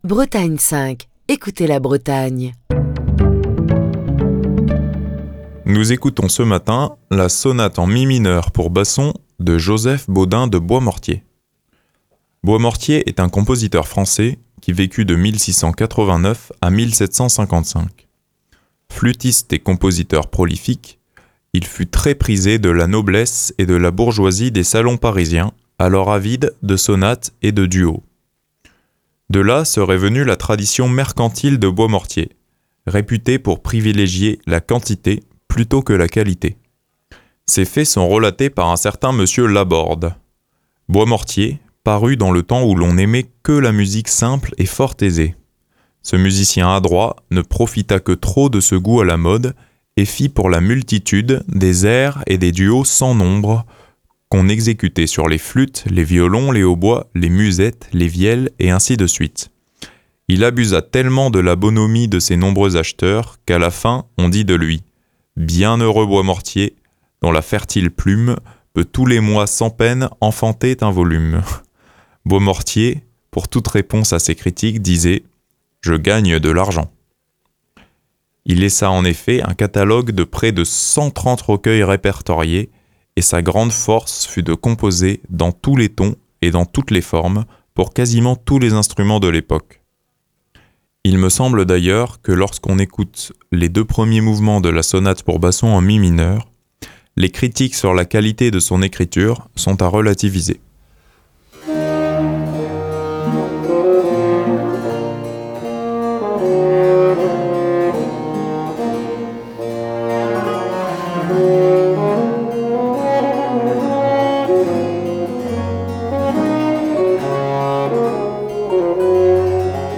Fil d'Ariane Accueil Les podcasts La sonate pour basson en mi mineur de Joseph Bodin de Boismortier La sonate pour basson en mi mineur de Joseph Bodin de Boismortier Émission du 11 mars 2024. Joseph Bodin de Boismortier est un compositeur français qui vécut de 1689 à 1755.